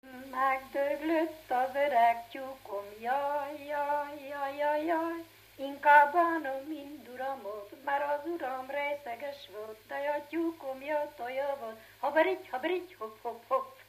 Erdély - Brassó vm. - Krizba
ének
Stílus: 7. Régies kisambitusú dallamok
Kadencia: (2) 1